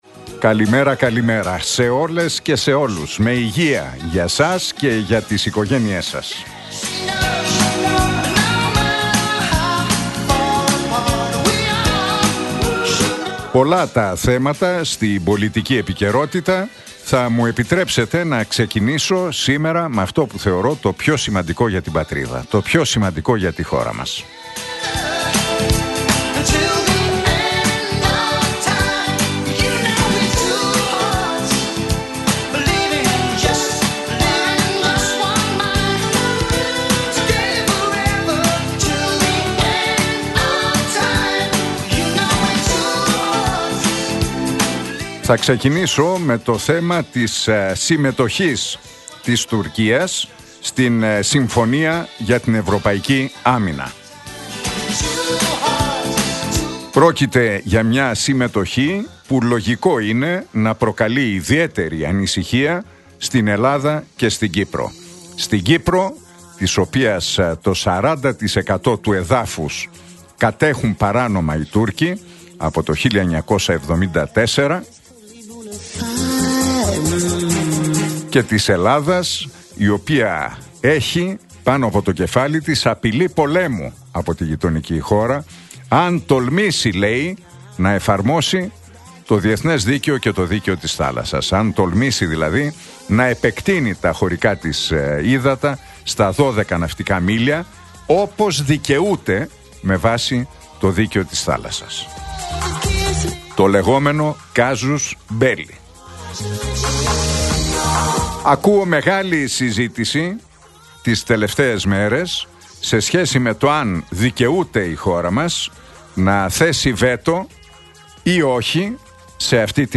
Ακούστε το σχόλιο του Νίκου Χατζηνικολάου στον ραδιοφωνικό σταθμό Realfm 97,8, την Τετάρτη 28 Μαΐου 2025.